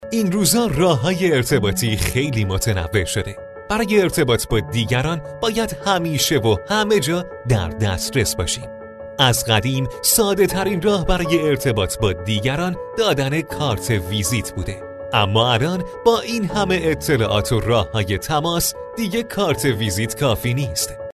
Male
Young
Adult
-Commercial